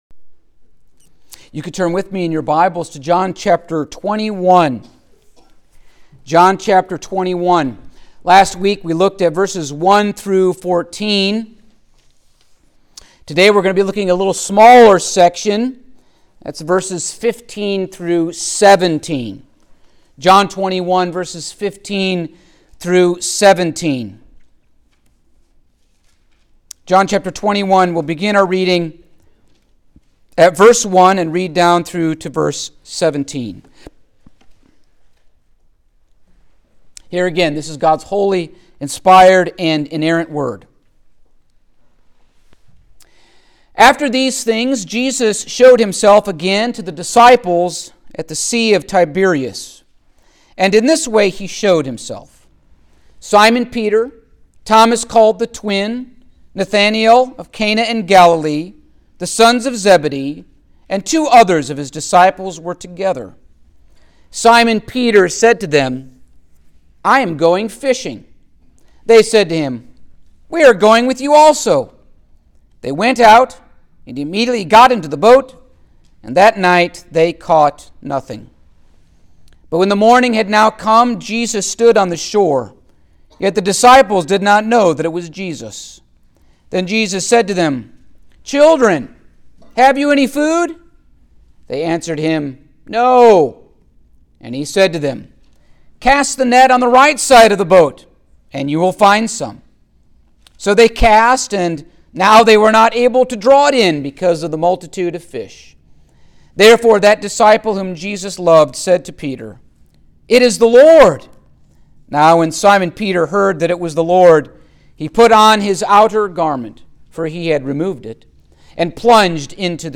Passage: John 21:15-17 Service Type: Sunday Morning